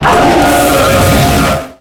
Cri de Kyurem Blanc dans Pokémon X et Y.
Cri_0646_Blanc_XY.ogg